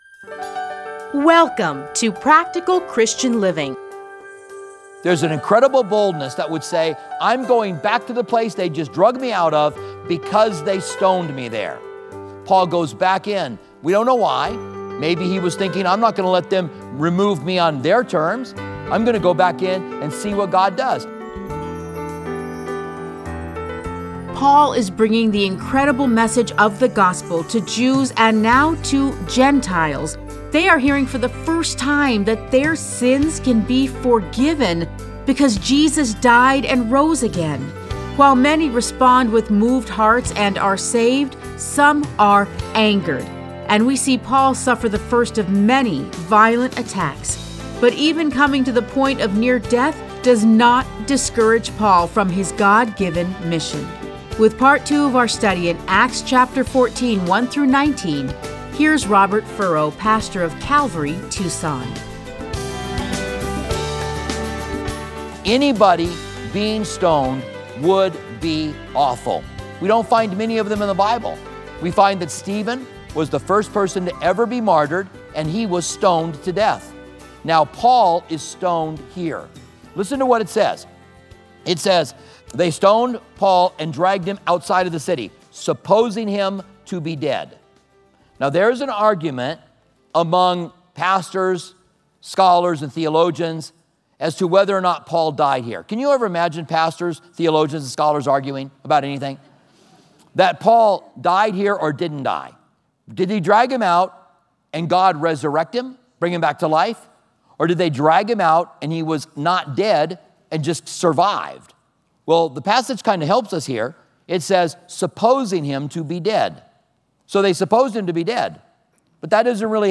Listen to a teaching from Acts 14:1-19.